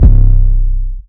808 [ tsunami ].wav